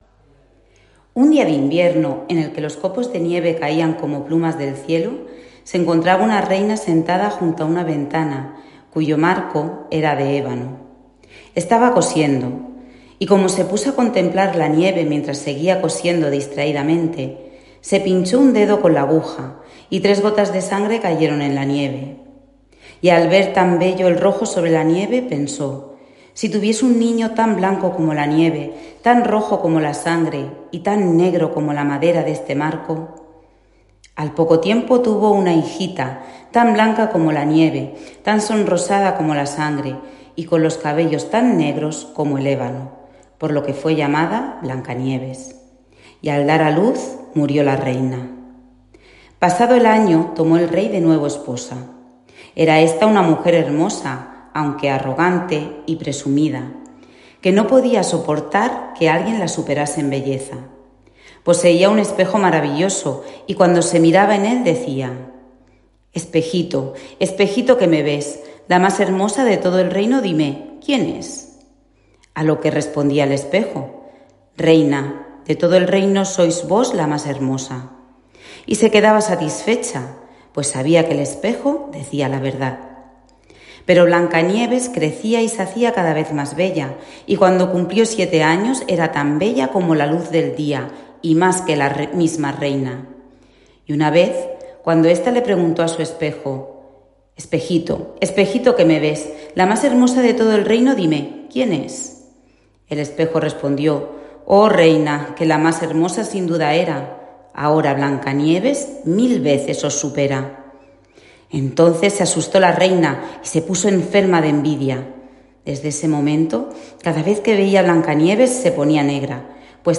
Fragmento de cuento narrado